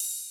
ride_cym3.ogg